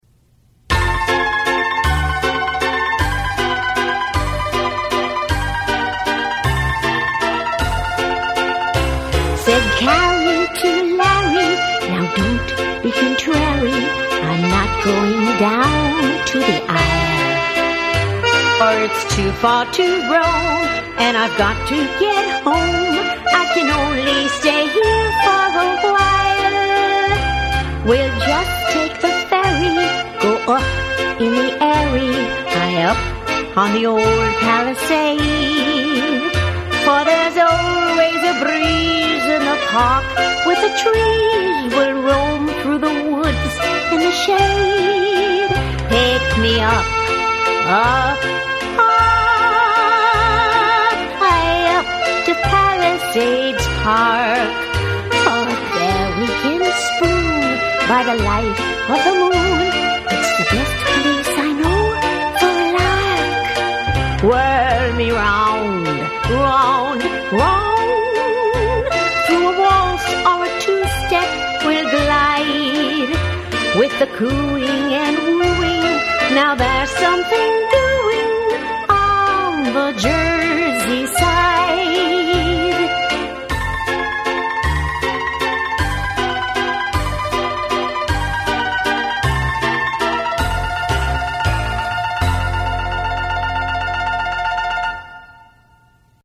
Vocal performance